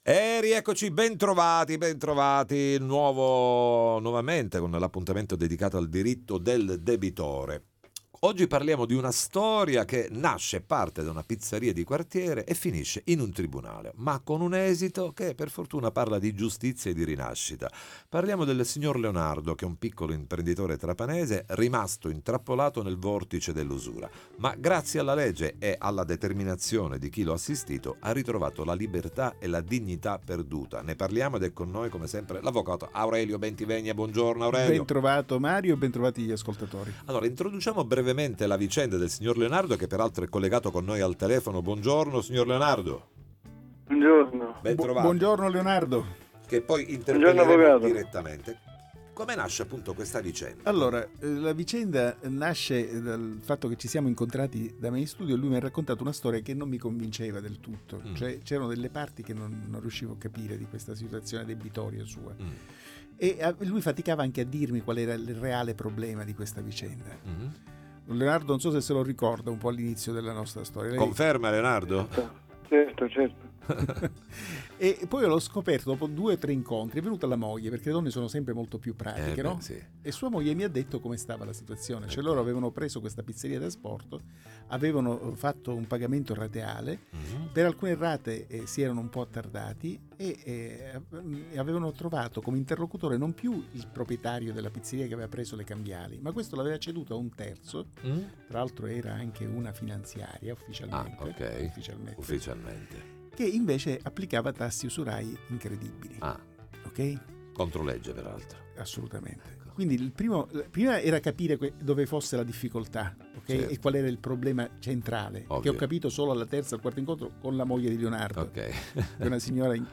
Il Diritto del Debitore: 8a Puntata Interviste Time Magazine 02/12/2025 12:00:00 AM / Time Magazine Condividi: Il Diritto del Debitore: 8a Puntata: insieme all’avv.